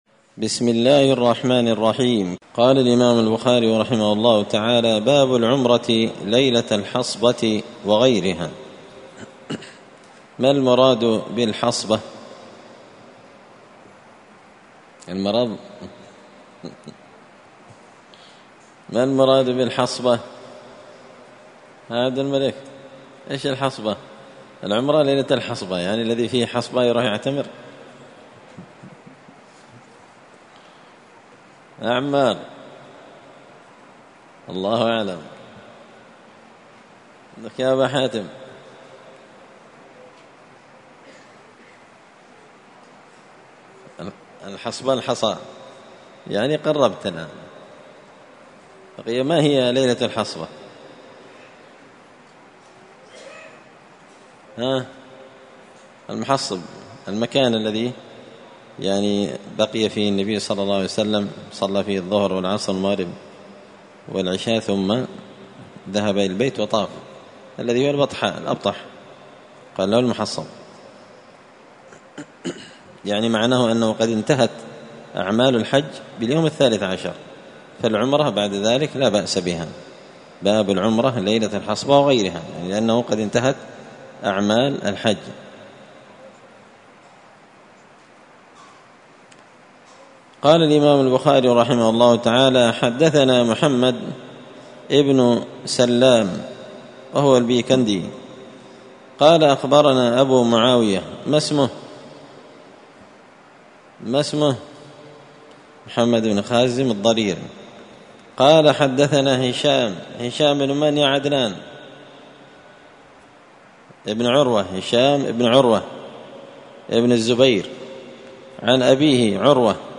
الأثنين 29 ذو الحجة 1444 هــــ | الدروس، شرح صحيح البخاري، كتاب العمرة | شارك بتعليقك | 11 المشاهدات